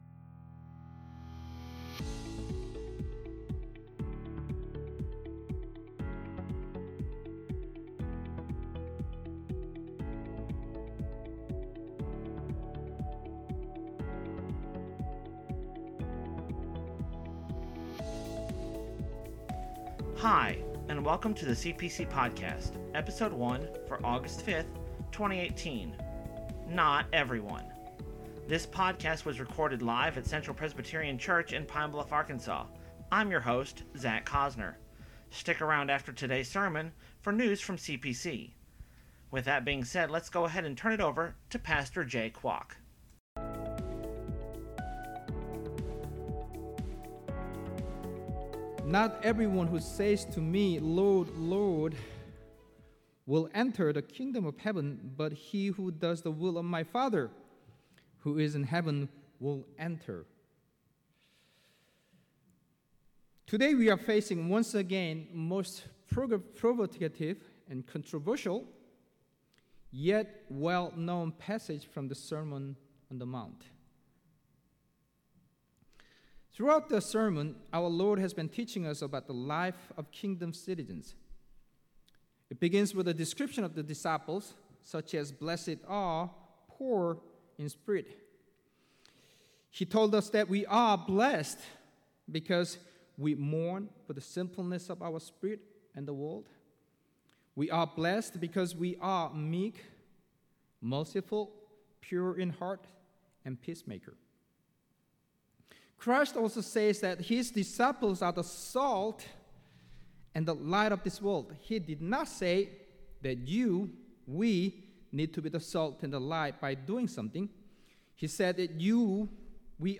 This week's sermon title is "Not Everyone!"